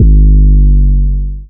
DDW4 808 4.wav